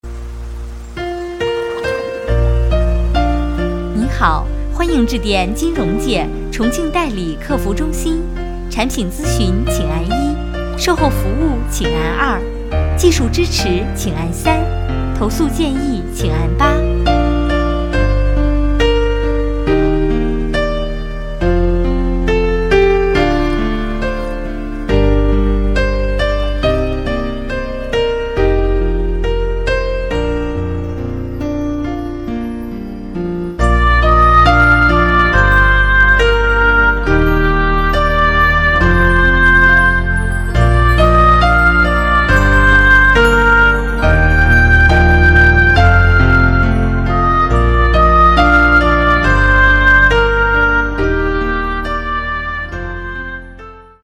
2 企业彩铃
录制费：100元/次 由专业播音主持、专业录音棚制作个性化彩铃。